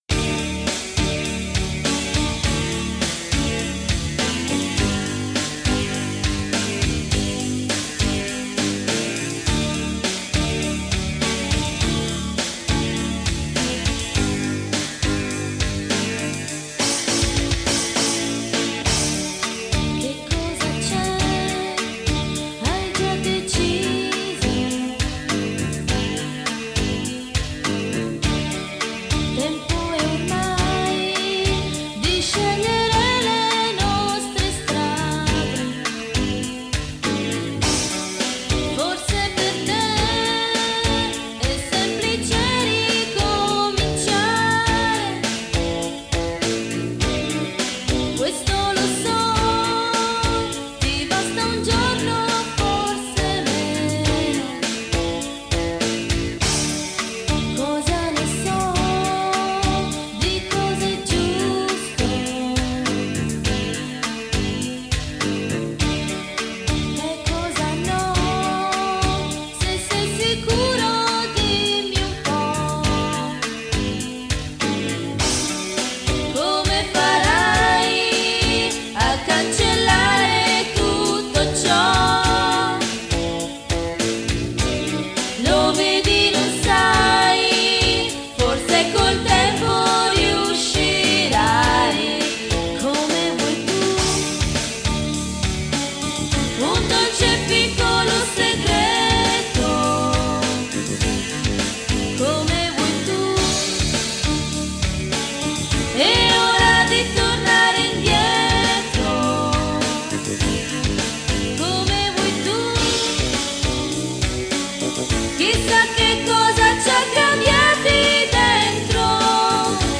• Piastra Pioneer CT-8R
• Multitrack Recorder Zoom MRS-4